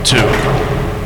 announcer_begins_2sec.mp3